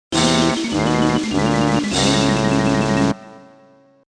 Challenge Lose Sound Effect - MP3 Download
Challenge-Lose-Sound-Effect.mp3